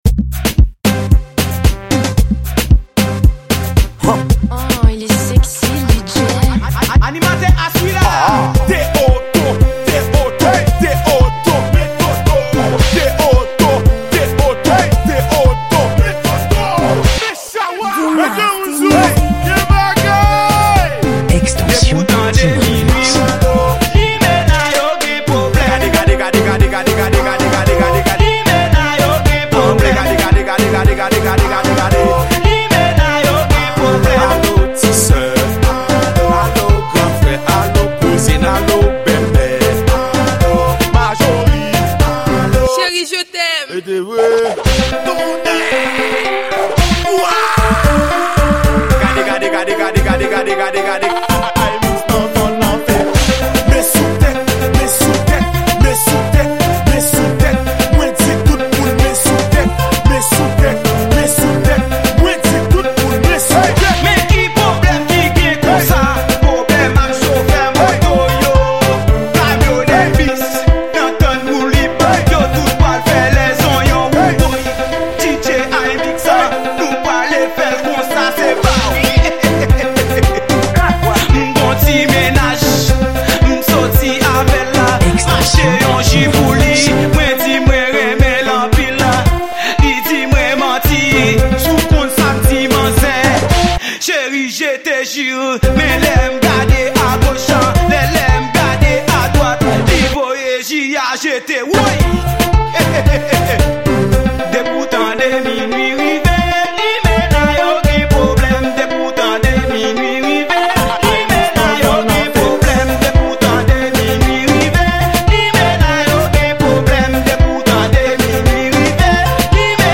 Genre: Mix